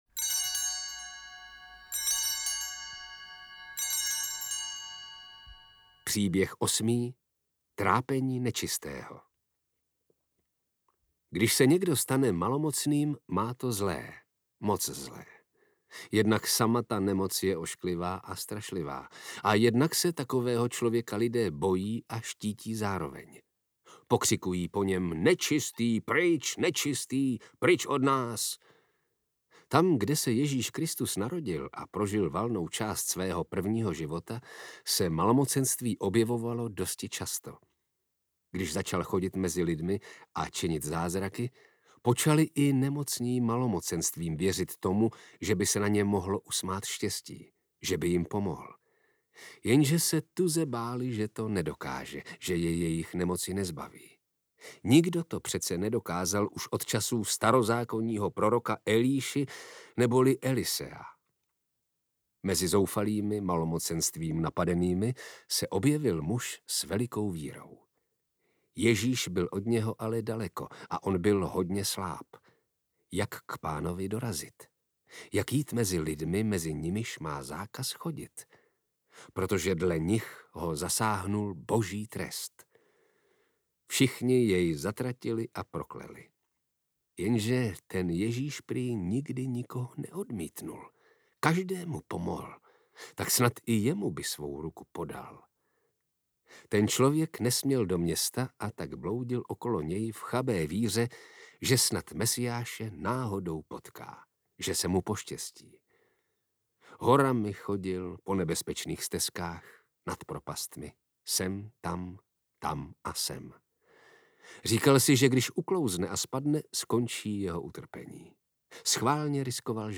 Ježíšek audiokniha
Ukázka z knihy